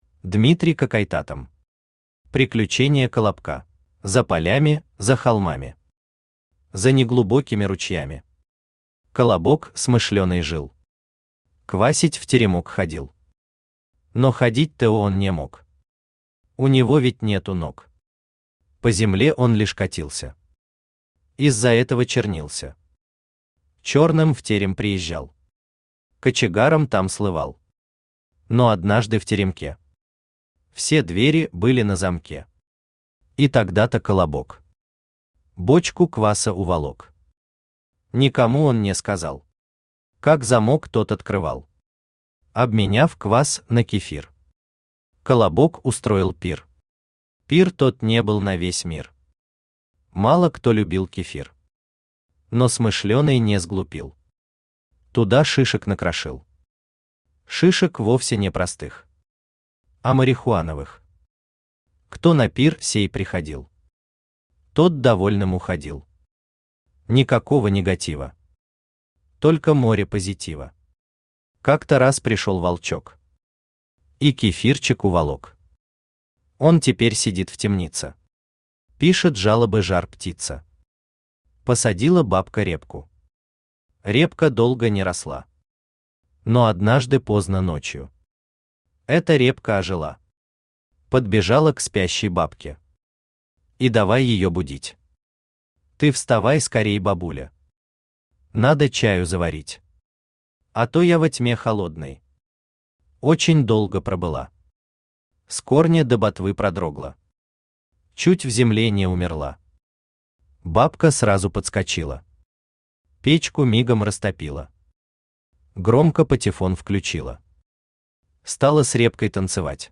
Аудиокнига Приключения Колобка | Библиотека аудиокниг
Aудиокнига Приключения Колобка Автор Дмитрий Какойтатам Читает аудиокнигу Авточтец ЛитРес.